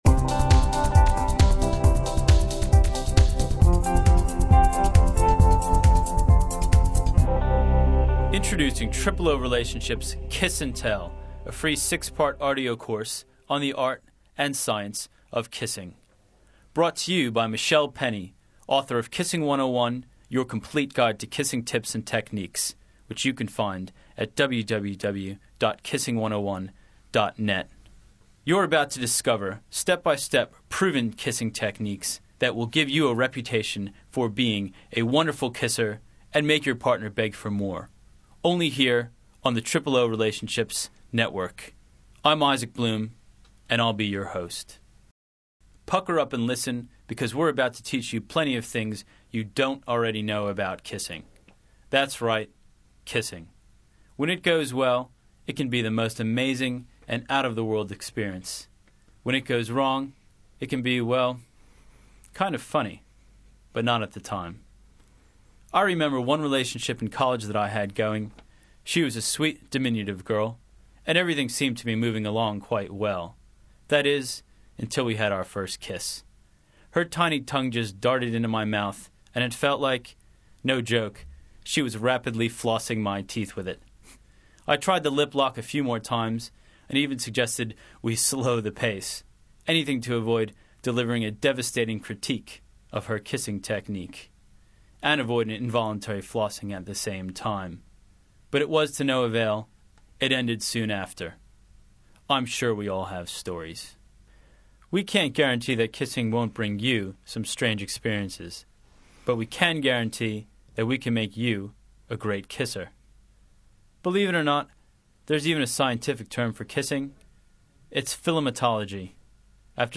If you want to know the answers, click on the PLAY button below to listen to the first lesson in the Kissing 101 Minicourse: "Kiss N Tell: Why Do We Kiss Anyway?"